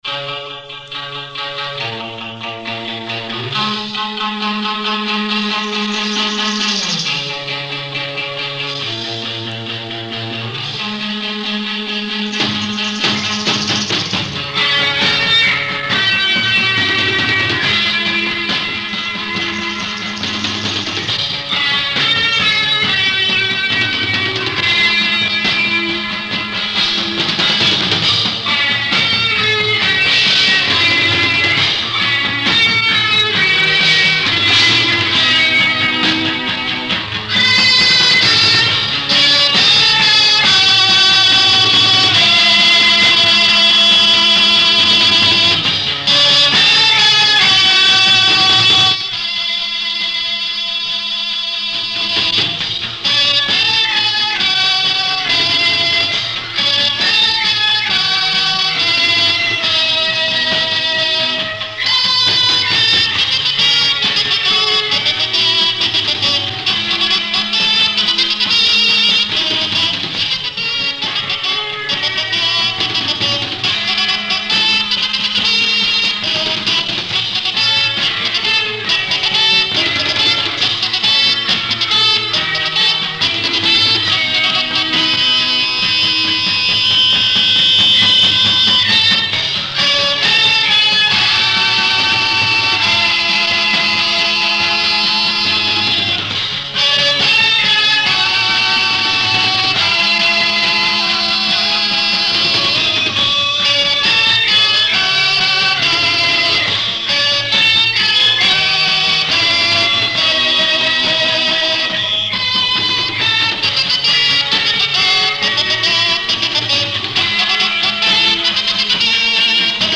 je česká avantgardní rocková kapela.